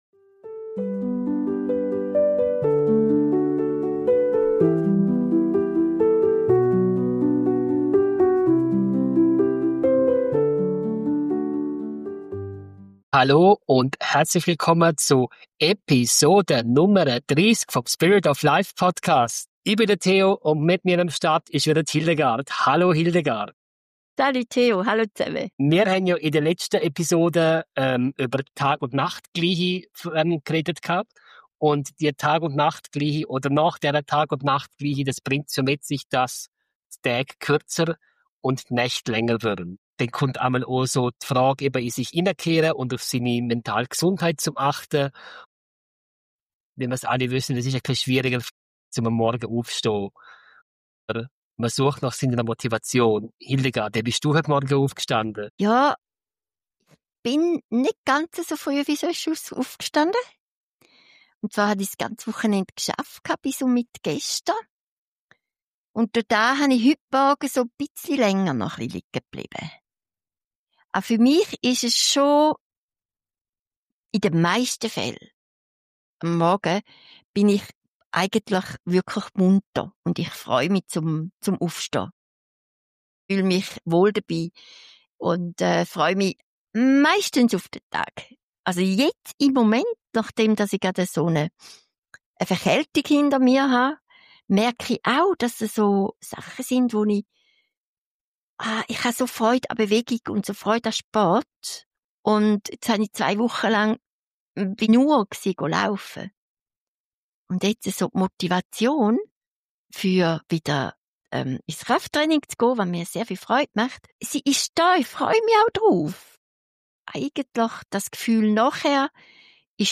Ein inspirierendes Gespräch über Selbstfürsorge, Achtsamkeit und die Kunst, immer wieder neu anzufangen, auch dann, wenn das Leben mal kurz klingelt und uns aus dem Flow holt.